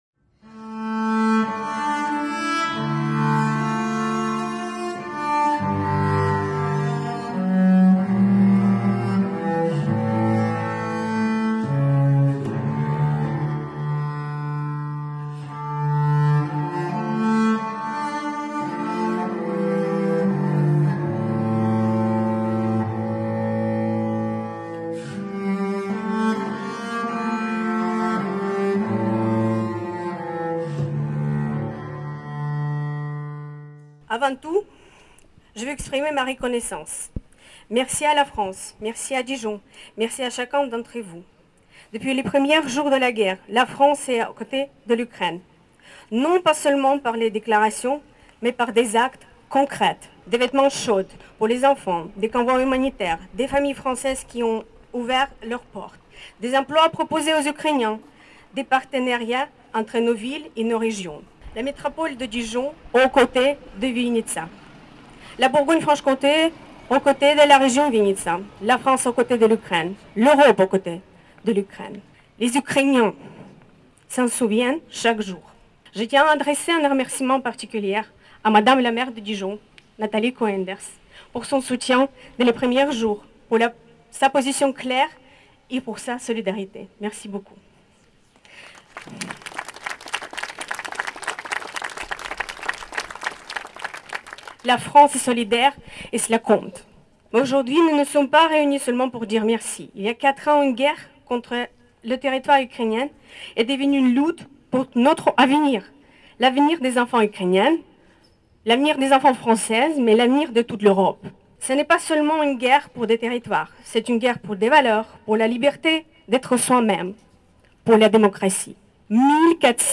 Cet hommage a été d'autant plus intense que la place de la Libération a raisonné de la langue ukrainienne, chaque texte ayant été lu dans les deux langues.